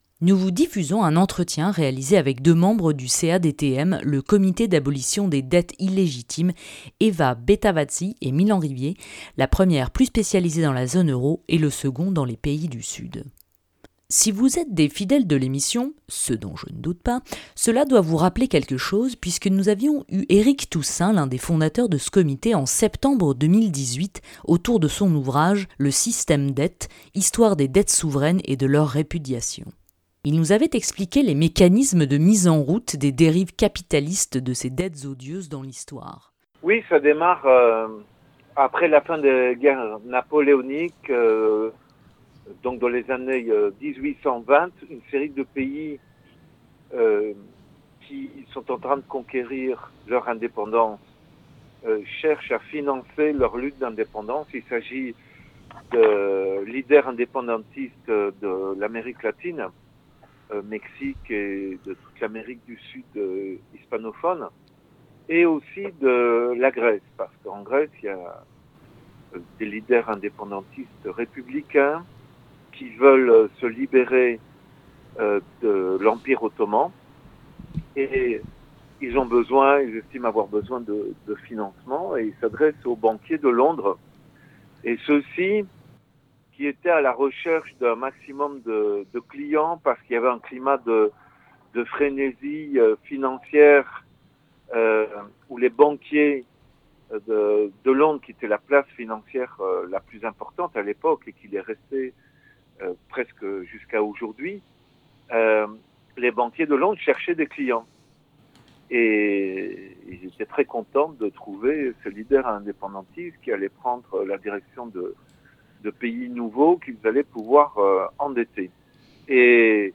On a discuté avec 2 représentant.e.s du CADTM, le comité de lutte pour l'abolition des dettes illégitimes, de la gestion de la crise sanitaire et des logiques d'endettement qui en découle.